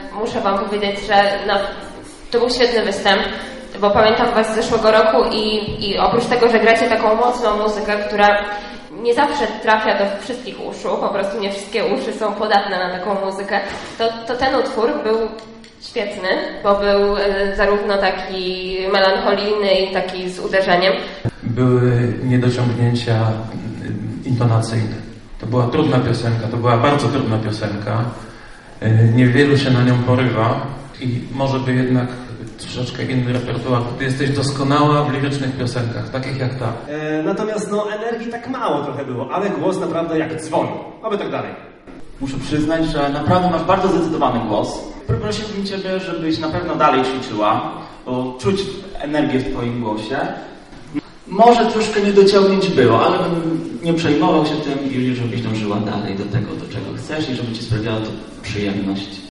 Komisja konkursowa po każdym z pokazów dzieliła się z publicznością swoimi opiniami.